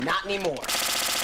This page contains an audio file that is either very loud or has high frequencies.